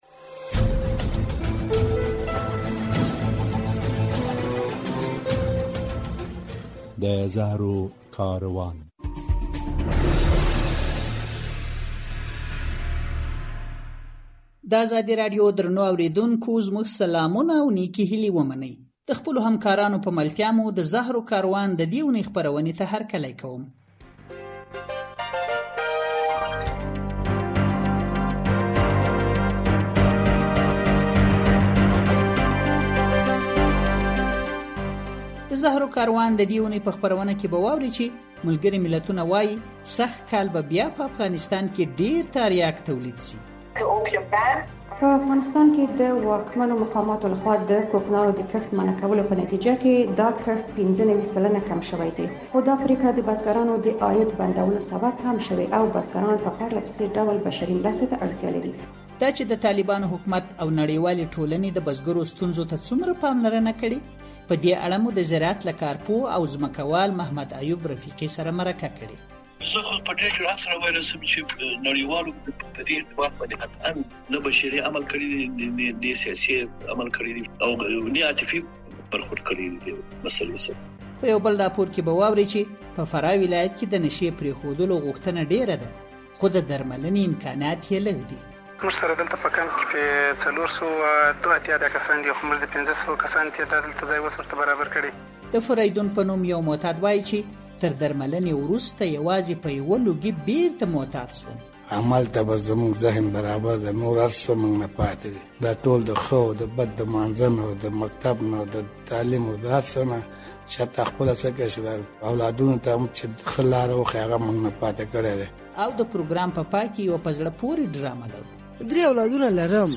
د زهرو کاروان په دې خپرونه کې به واورئ چې ملګري ملتونه وایي، سږ کال به بیا په افغانستان کې ډېر تاریاک تولید شي. په خپرونه کې د کوکنارو د کښت دوام او له نشه‌یي توکو سره د طالبانو حکومت د مبارزې پر لارو چارو له یوه شنونکي سره مرکه شوې ده.